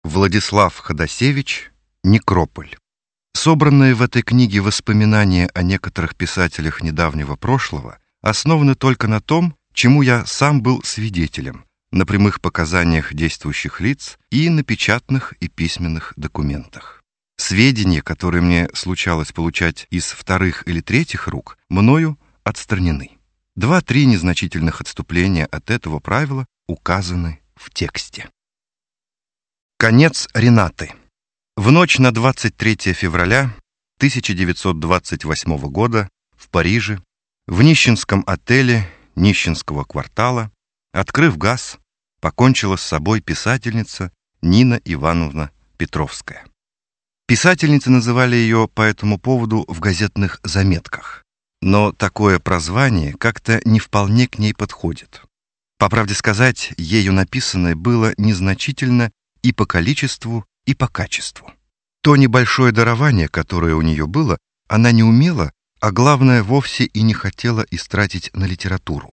Аудиокнига Некрополь | Библиотека аудиокниг